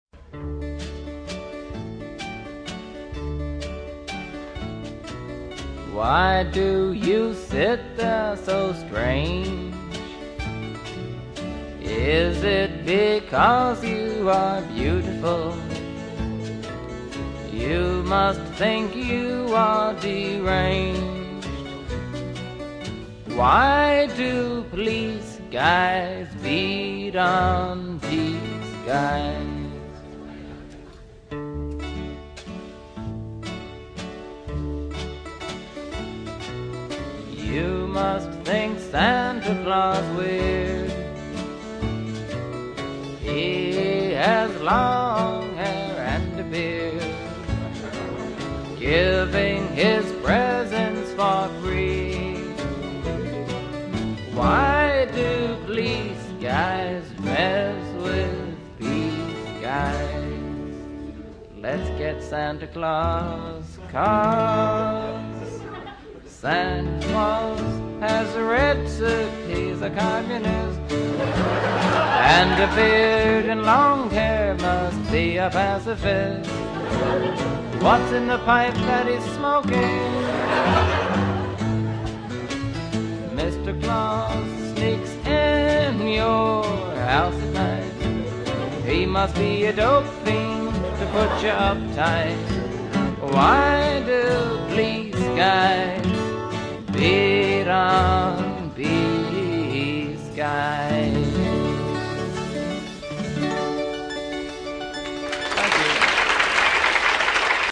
1960's folk